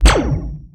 laser_shoot.wav